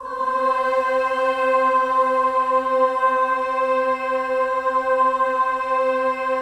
VOWEL MV09-L.wav